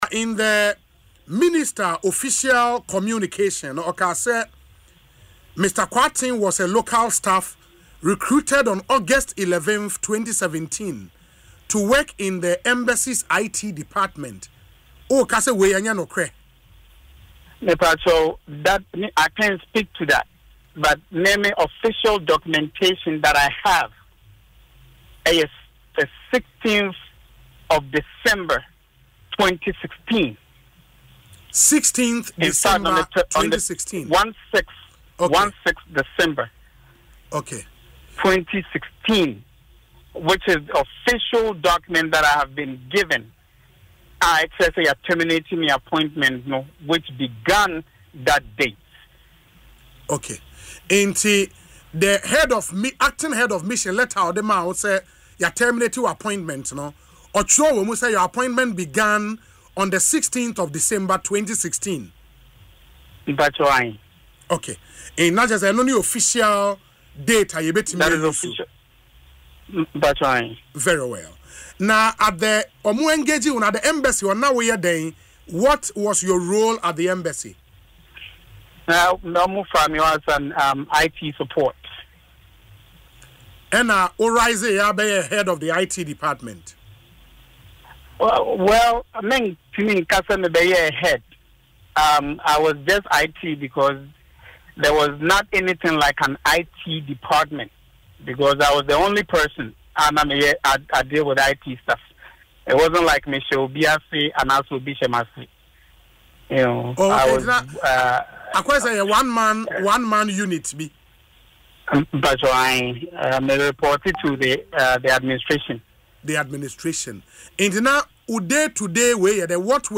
Speaking in an interview on Asempa FM’s Ekosii Sen